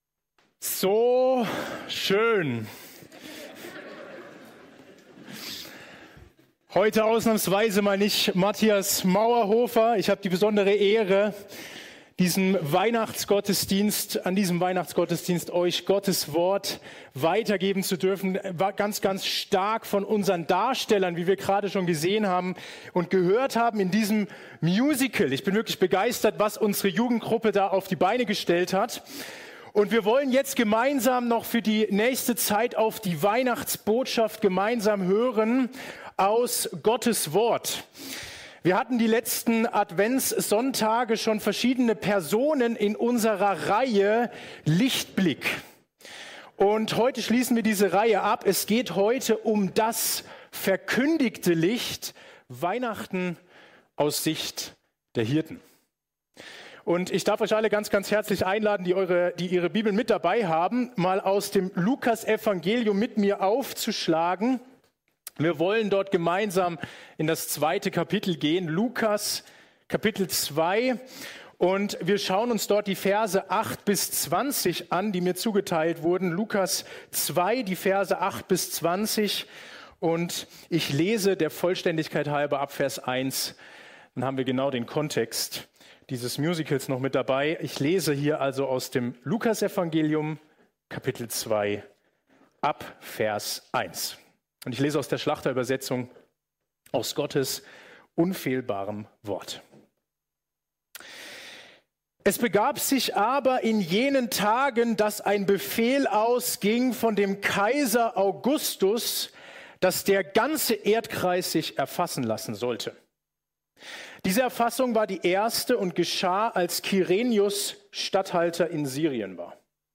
Kategorie: Weihnachtsgottesdienst